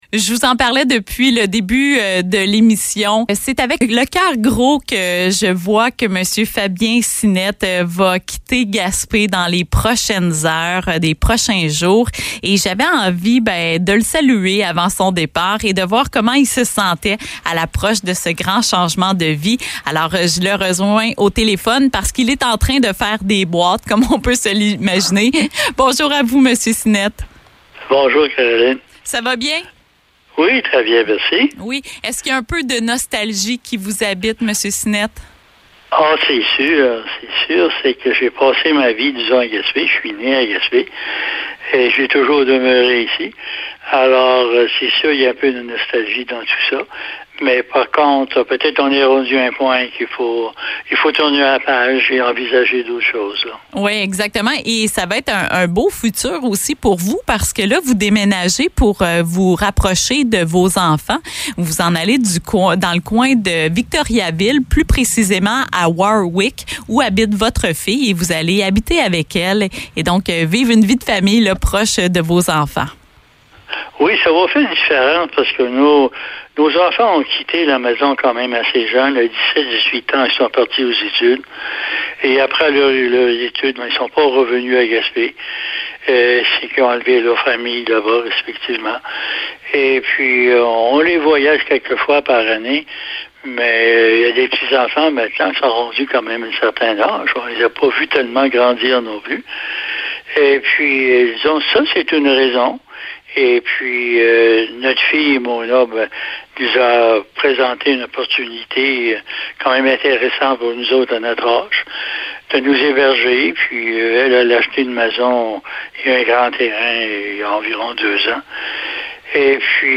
notre animatrice